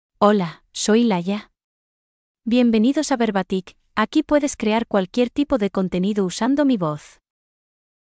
Laia — Female Spanish (Spain) AI Voice | TTS, Voice Cloning & Video | Verbatik AI
FemaleSpanish (Spain)
Laia is a female AI voice for Spanish (Spain).
Voice sample
Listen to Laia's female Spanish voice.
Laia delivers clear pronunciation with authentic Spain Spanish intonation, making your content sound professionally produced.